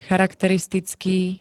charakteristický [-t-t-] -ká -ké 2. st. -kejší príd.